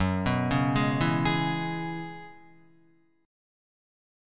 F#7sus2sus4 chord